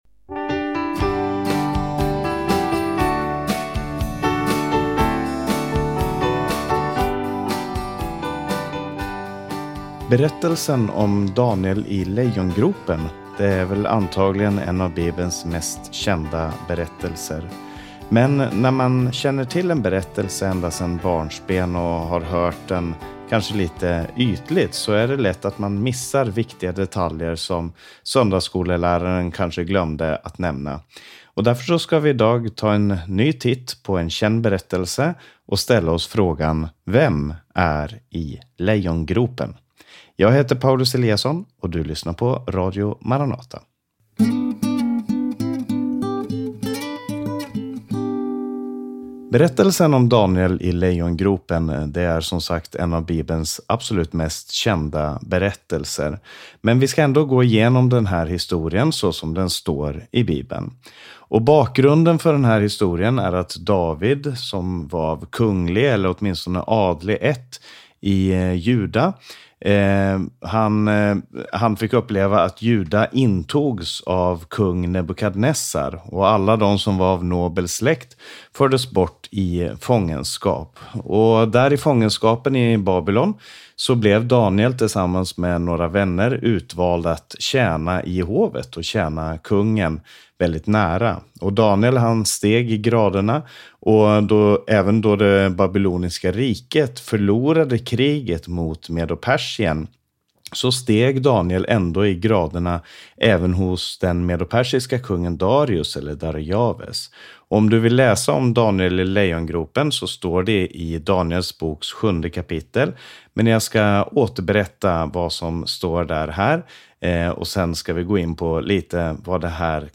Sång: "Namnet Jesus" med Maranataförsamlingen.